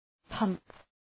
Προφορά
{pʌmp}